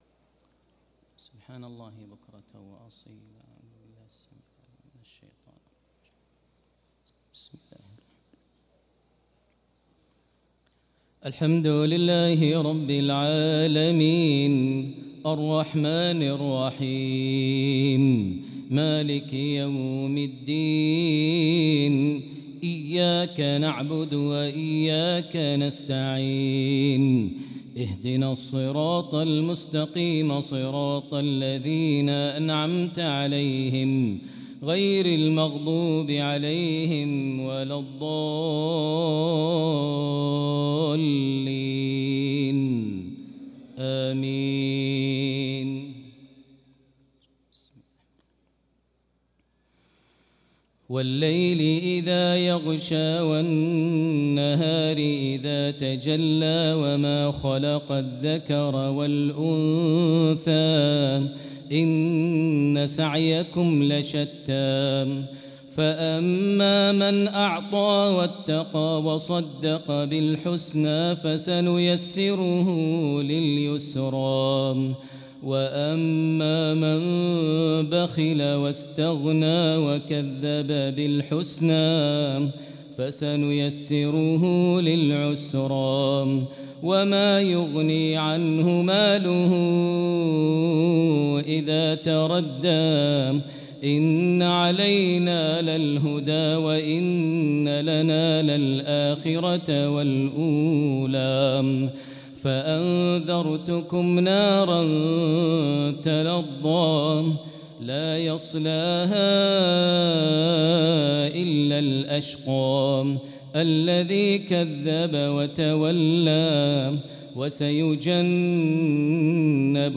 صلاة المغرب للقارئ ماهر المعيقلي 2 شعبان 1445 هـ
تِلَاوَات الْحَرَمَيْن .